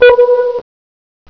Sonar688.wav